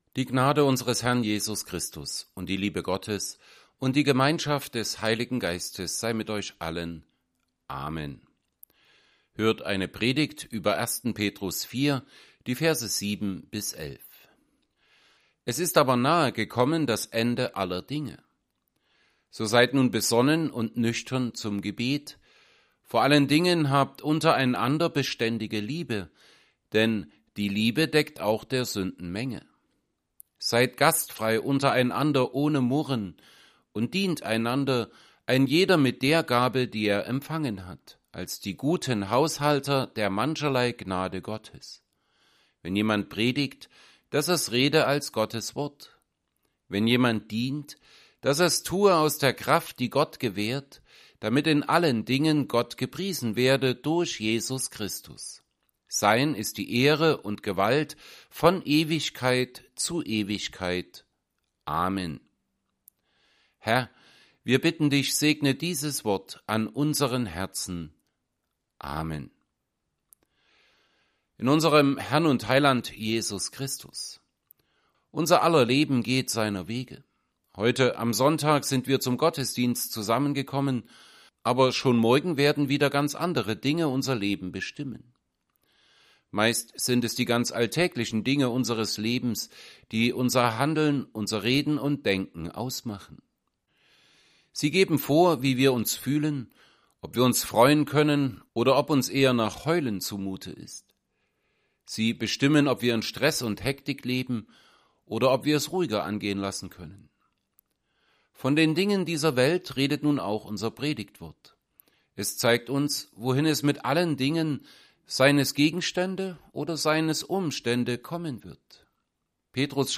Serie: Epistelpredigten Passage: 1 Peter 4:7-11 Gottesdienst
Predigt_1Petr_4_7b11.mp3